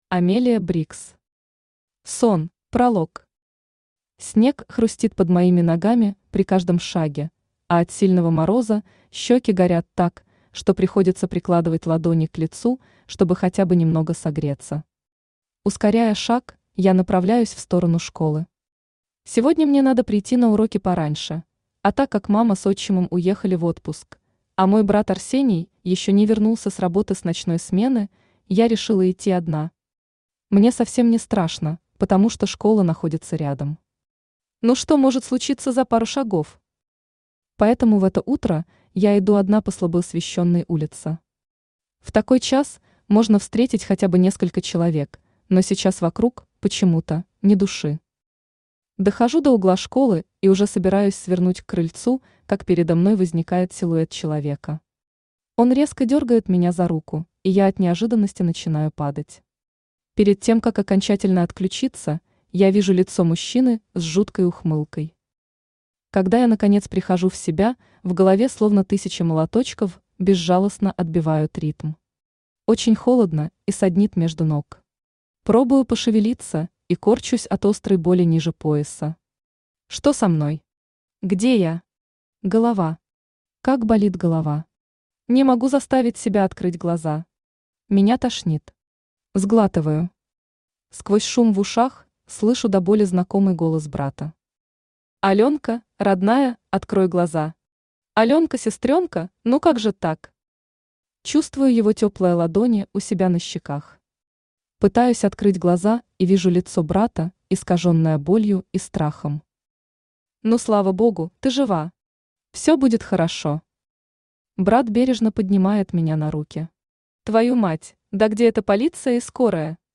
Aудиокнига Сон Автор Амелия Брикс Читает аудиокнигу Авточтец ЛитРес.